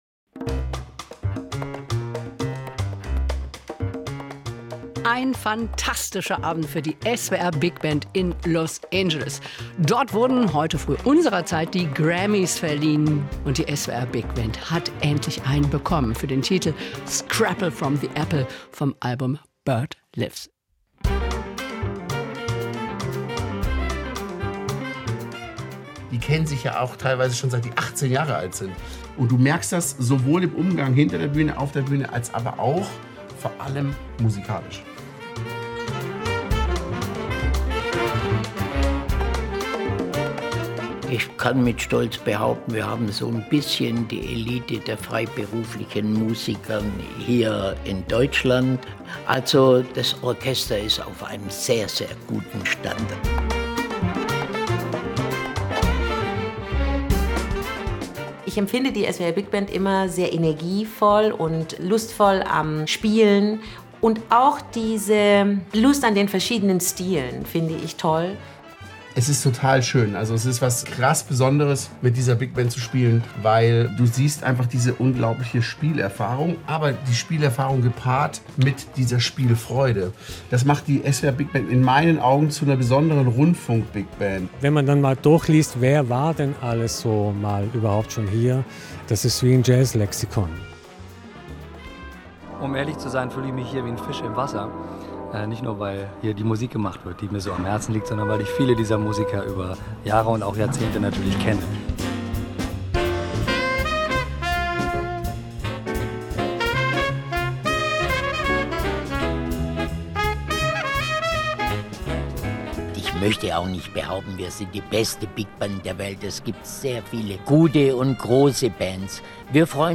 Collage